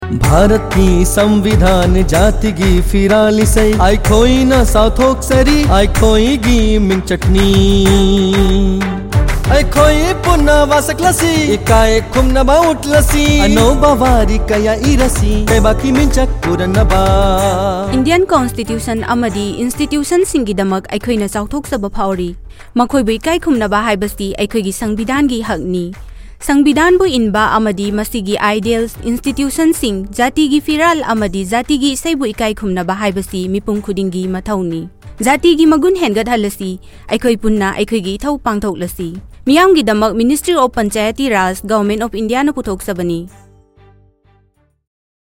135 Fundamental Duty 1st Fundamental Duty Abide by the Constitution and respect National Flag and National Anthem Radio Jingle Manipuri